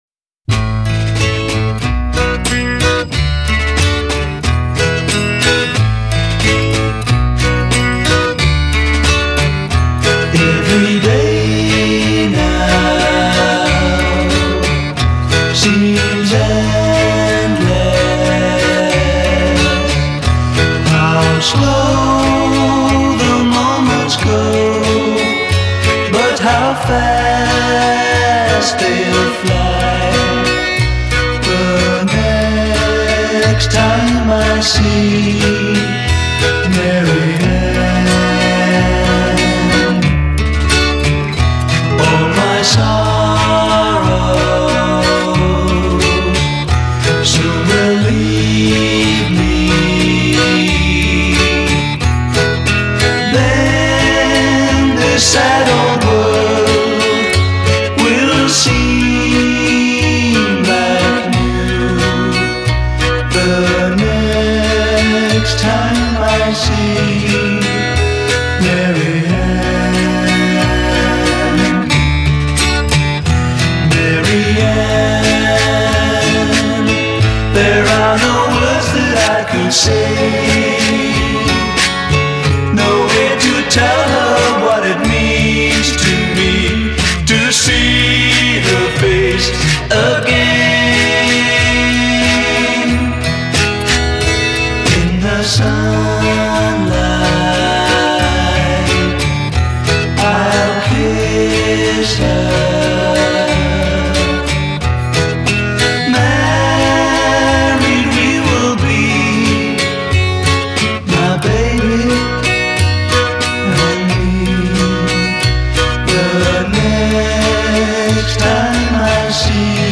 Original Mono